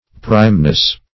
Meaning of primeness. primeness synonyms, pronunciation, spelling and more from Free Dictionary.